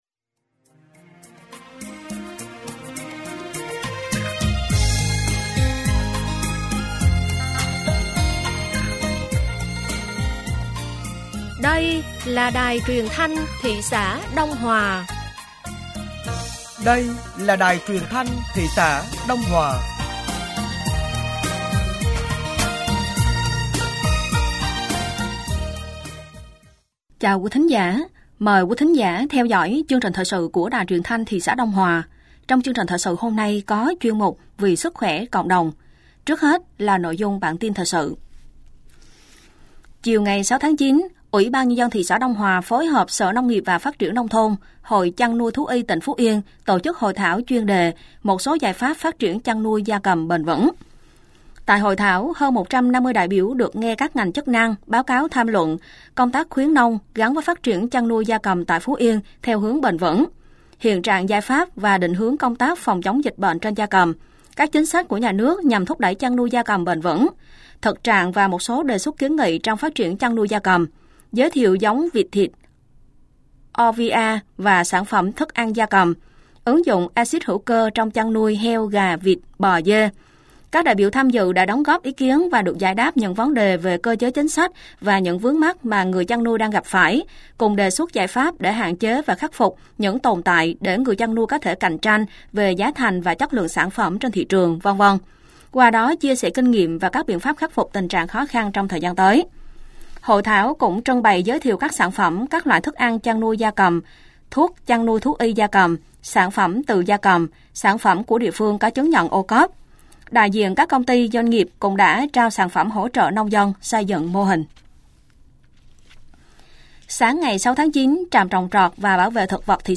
Thời sự tối ngày 06 và sáng ngày 07 tháng 9 nămn 2023